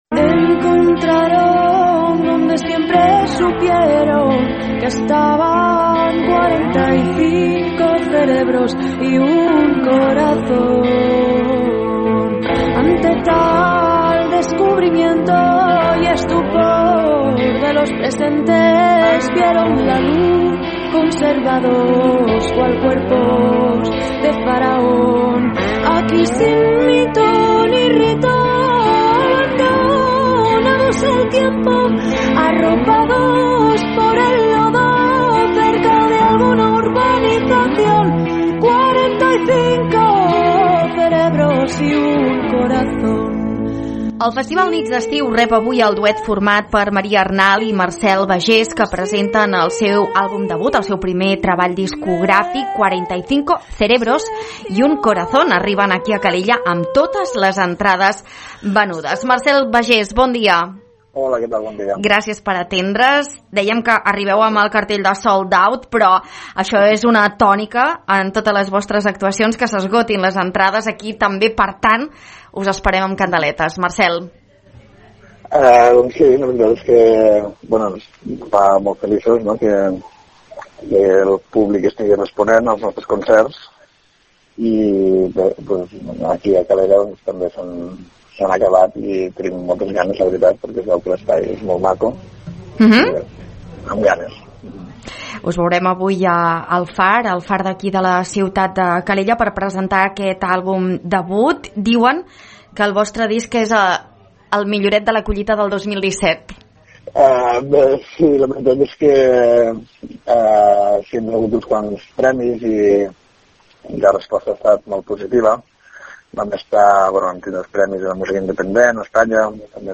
En una entrevista a Ràdio Calella TV, Marcel Bagés ha dit que esperen amb il·lusió el concert d’aquesta nit al Far.
3081-ENTREVISTA-NEC-WEB.mp3